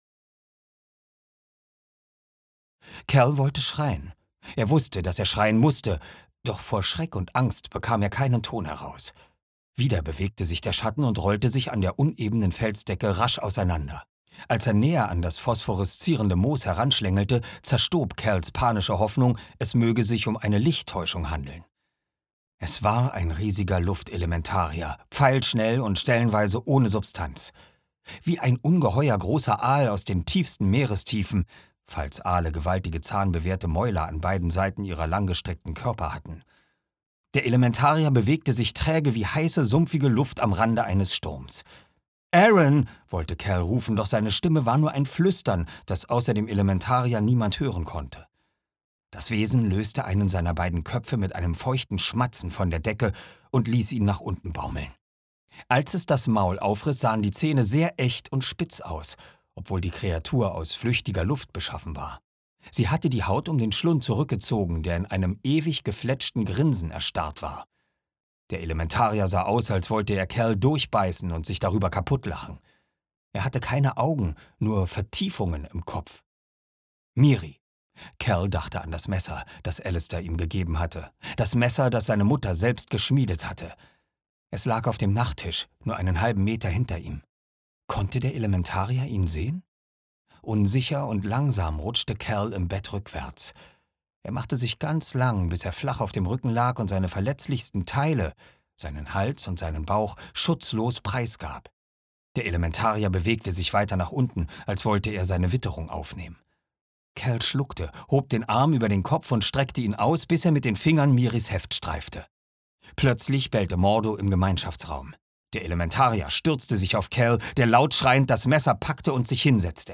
Oliver Rohrbeck (Sprecher)
Jahrhundert • Abenteuer; Kinder-/Jugendliteratur • All Age • Bestsellerreihe • Bücher ab 12 Jahre • Bücher ab 14 Jahre • Bücher für Mädchen • Bücher für Teenager • Cassandra Clare • Cornelia Funke • Der kupferne Handschuh • Der Weg ins Labyrinth • Die silberne Maske • Dystopie • Fantasy; Kinder-/Jugendliteratur • Freundschaft • für Jugendliche • Holly Black • Hörbuch; Lesung für Kinder/Jugendliche • John Greene • Jugendbuch • Jugendbücher • Junge Belletristik • Junge Erwachsene • Kinder, Jugendliche und Bildung • Magie • New Adult • Romane für Jugendliche • Romantik • Schicksal • Stephenie Meyer • Teenager • Teenies • Teens • The Bronze Key • The Golden Boy • tribute von panem • Twilight • USA • YA • Young Adult • Zauber • Zauberschule